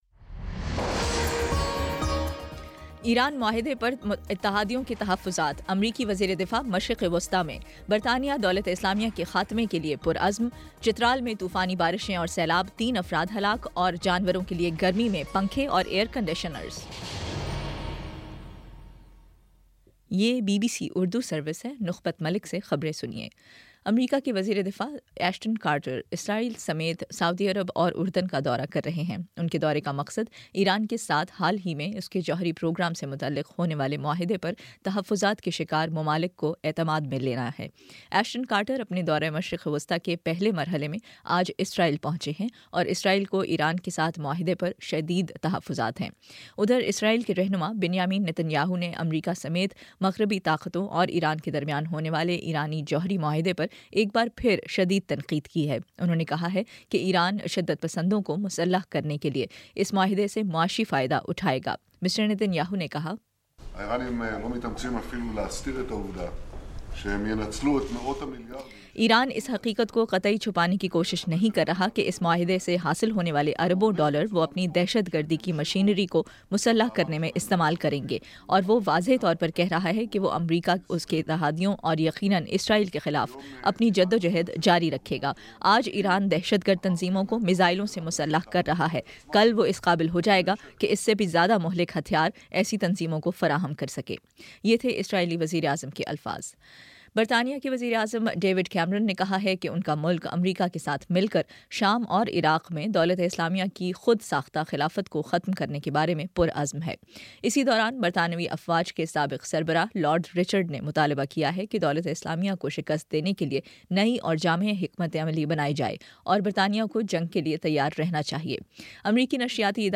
جولائی 19: شام چھ بجے کا نیوز بُلیٹن